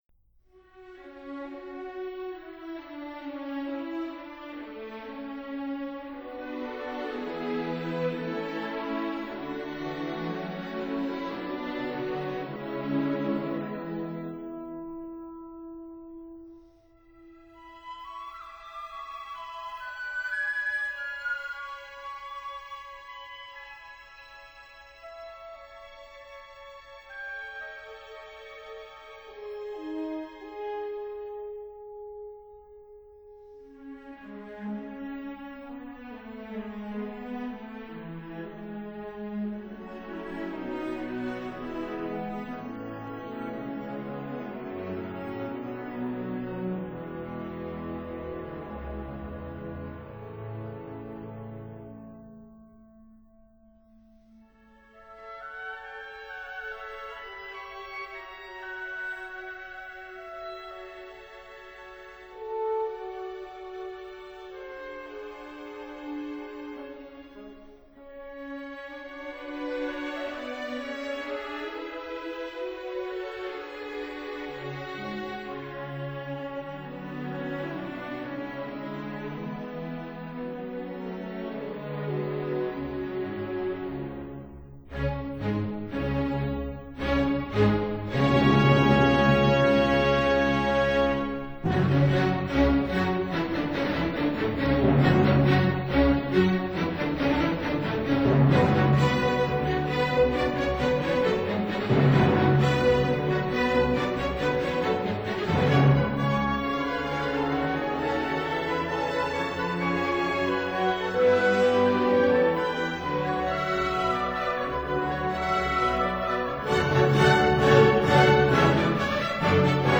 Symphony No. 2 in F sharp minor, Op. 16